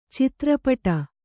♪ citra paṭa